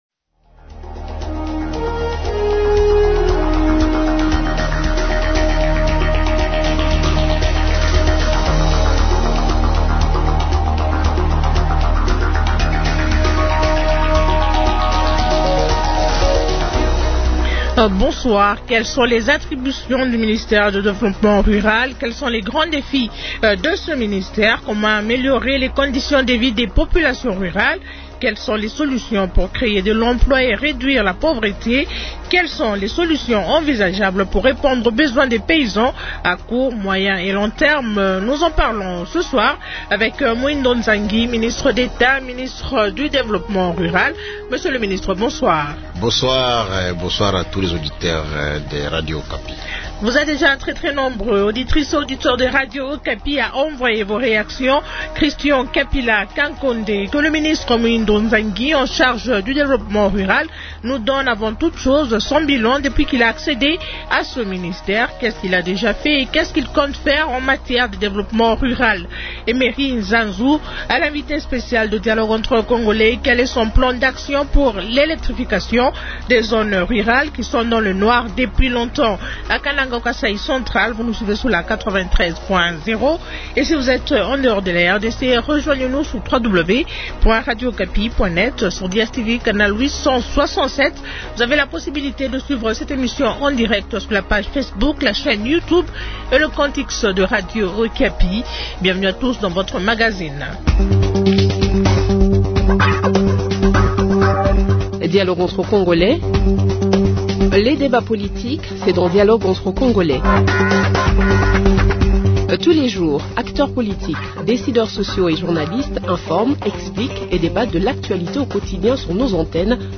Dialogue entre Congolais fait le point avec le ministre d’Etat, Ministre du développement rural.